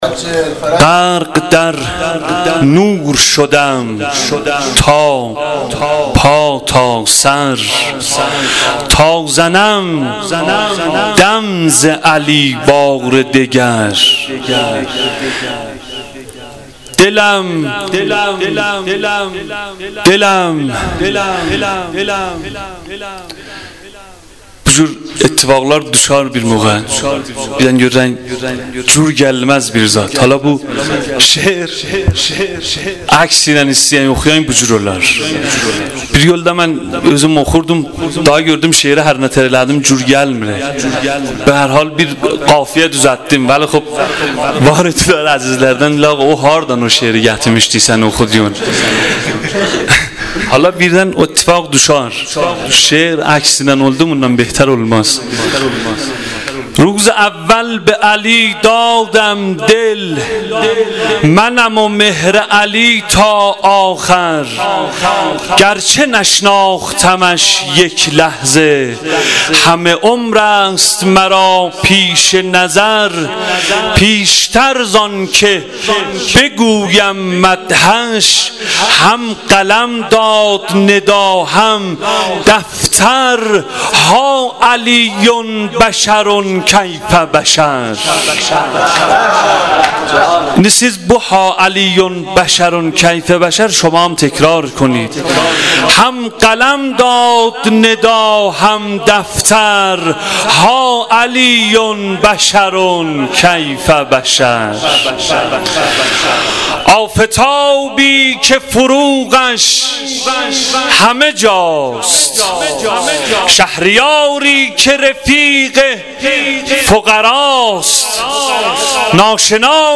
دکلمه خوانی شب میلاد امام علی (علیه السلام)